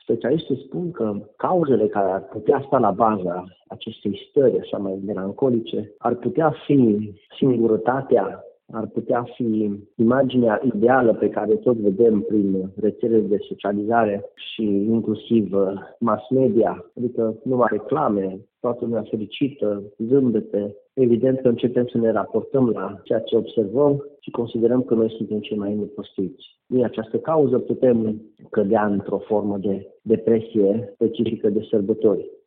Psihologul